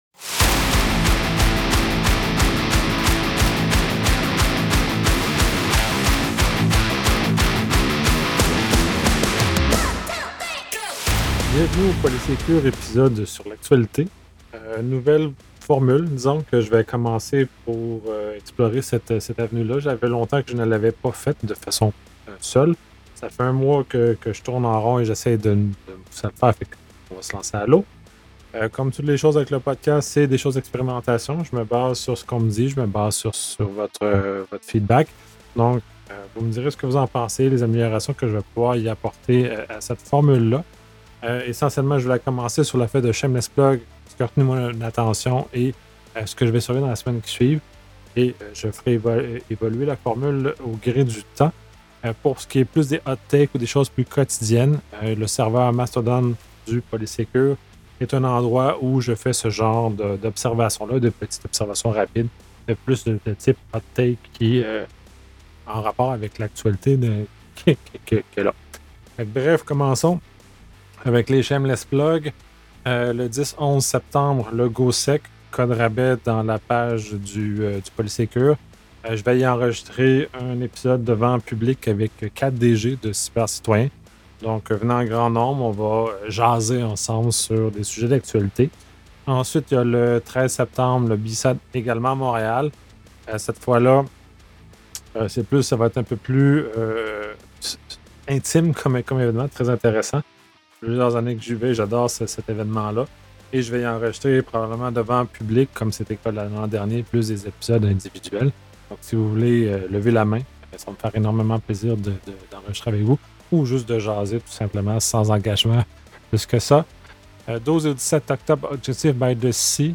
Bon… je saute à l’eau et je repars un podcast sur l’actualité en mode seul.
Aussi, et probablement le plus audible, j’ai eu un glitch à l’enregistrement.
Pour l’aspect technique, j’ai oublié de retirer un filtre lors de l’enregistrement, ce qui fait que la bande originale est “instable”.